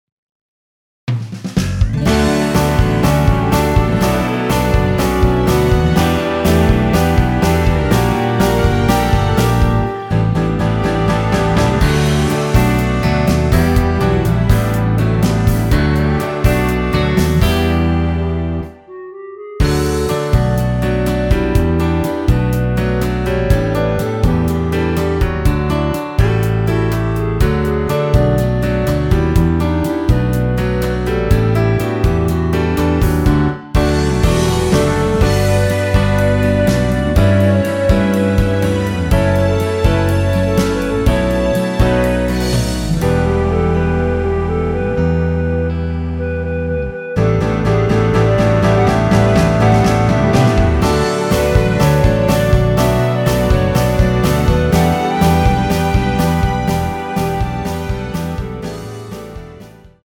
원키에서(-1)내린 멜로디 포함된 MR 입니다.
D
◈ 곡명 옆 (-1)은 반음 내림, (+1)은 반음 올림 입니다.
앞부분30초, 뒷부분30초씩 편집해서 올려 드리고 있습니다.